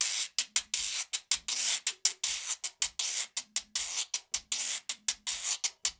80 GUIRO 1.wav